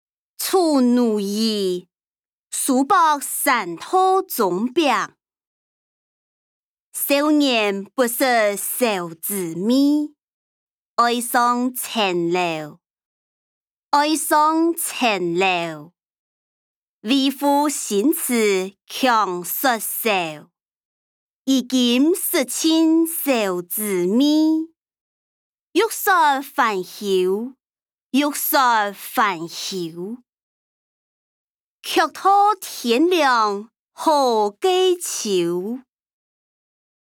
詞、曲-醜奴兒•書博山道中壁音檔(四縣腔)